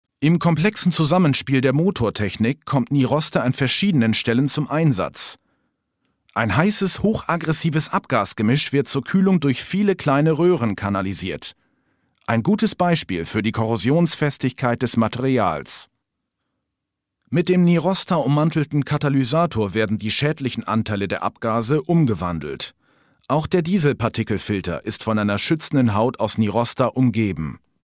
Vielseitig einsetzbare sonore warme Stimme für Synchron, Dokumentation, Werbung, Industriefilm, E-Learning usw.
Kein Dialekt
Sprechprobe: Industrie (Muttersprache):
german voice over artist